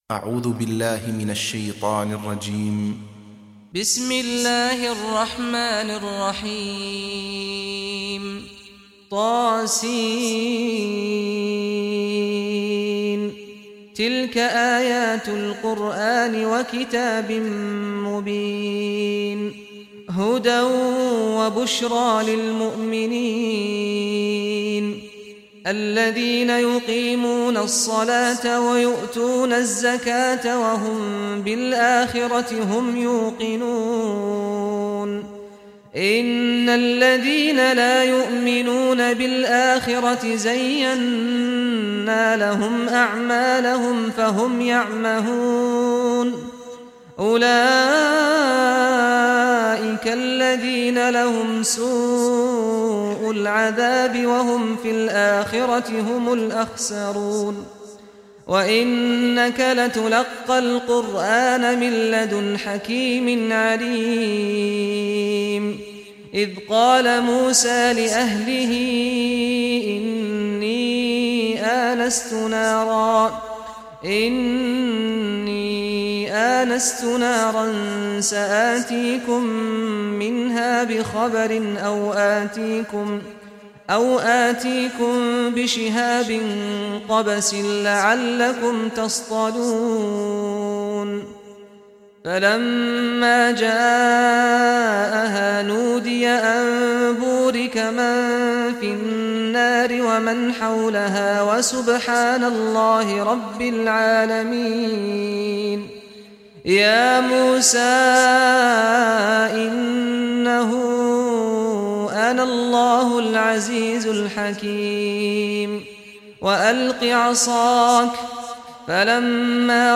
Surah An-Naml Recitation by Sheikh Saad al Ghamdi
27-surah-naml.mp3